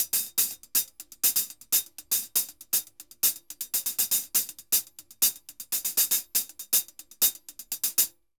HH_Merengue 120_1.wav